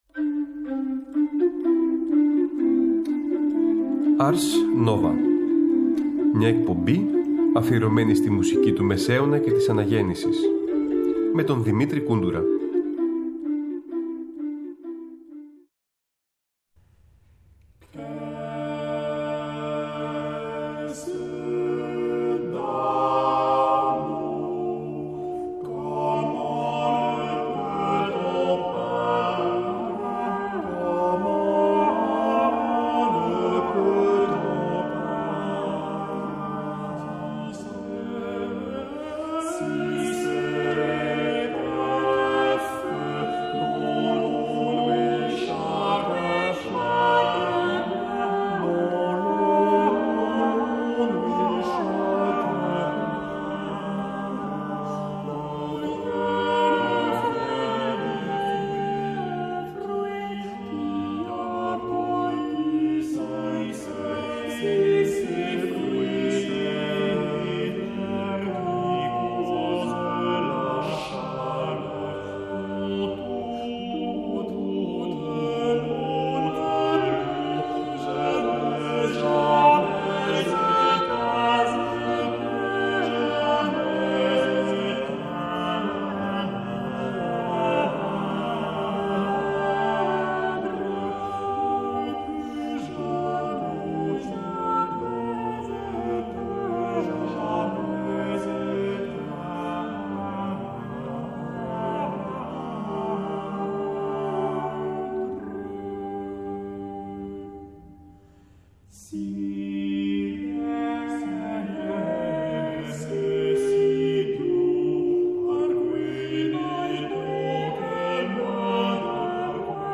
Ένα μουσικό ταξίδι στο Παρίσι του 16ου αιώνα μέσα από τα πολυφωνικά τραγούδια των Clément Janequin, Thomas Crecquillon, Claudin de Sermisy και κοσμική ποίηση της μουσικής.